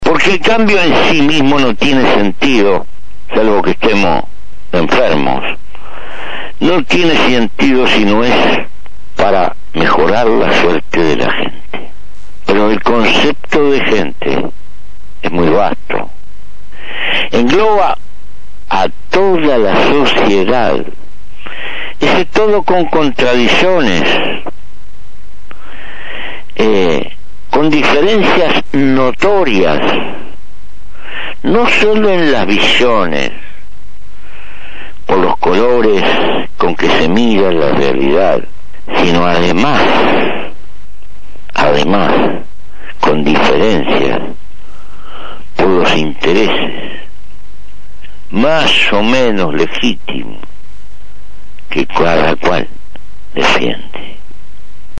Audición radial